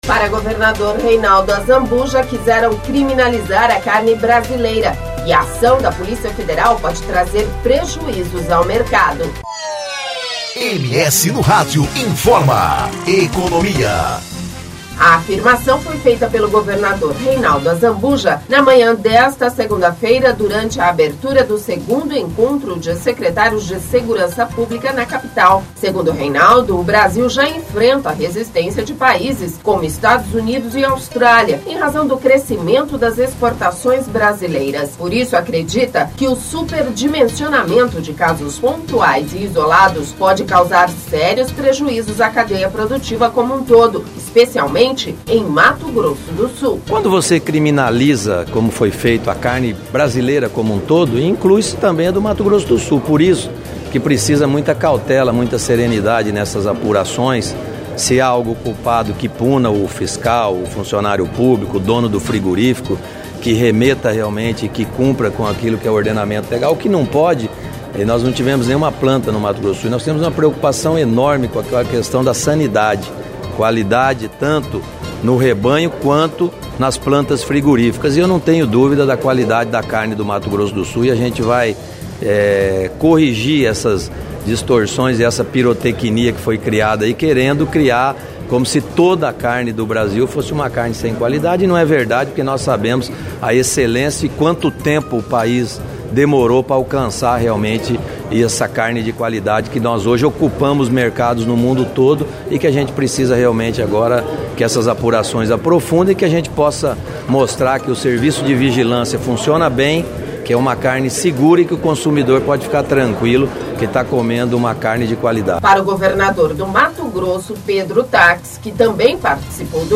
A afirmação foi feita pelo Governador Reinaldo Azambuja, na manhã desta segunda-feira(20), durante abertura do Encontro de Secretários de Segurança Pública, na Capital.